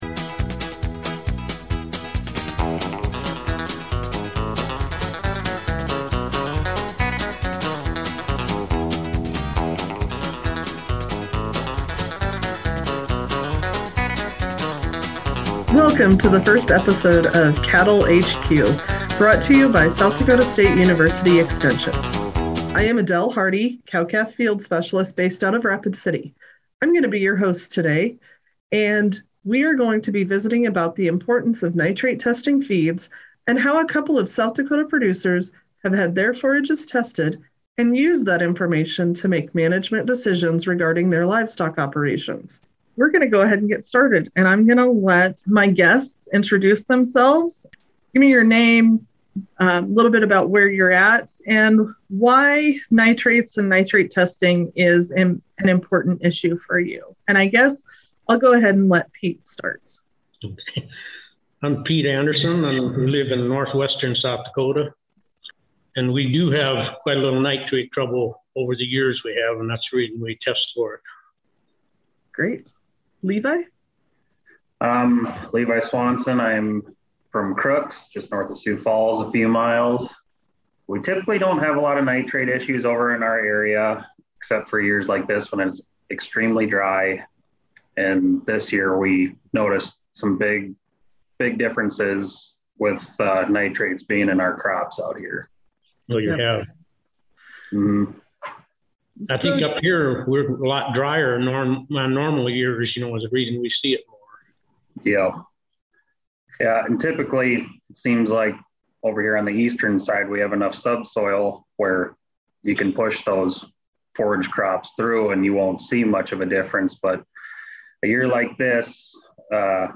talks with various cow/calf producers about the subject of nitrate testing feeds, and how that process is used in their day to day operations.